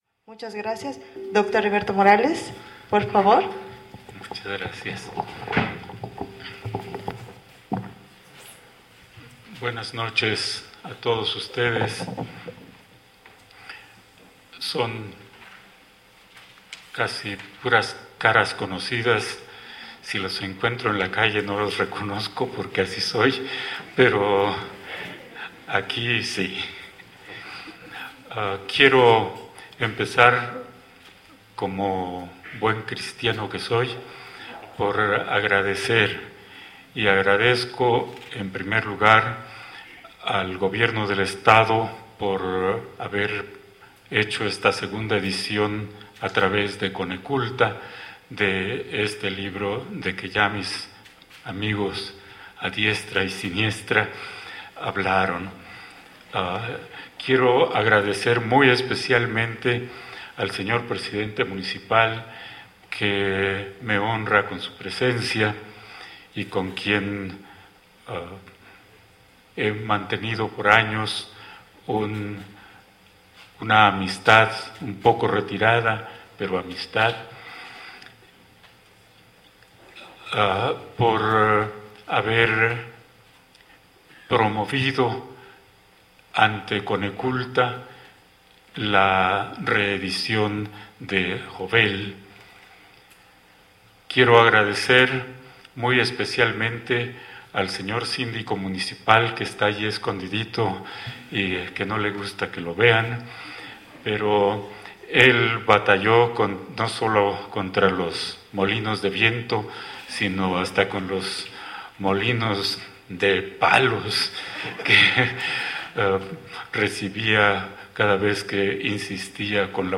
Lugar:San Cristóbal de Las Casas, Chiapas. Mexico.
Equipo: Grabadora Sony ICD-UX80 Stereo, Micrófono de construcción casera (más info)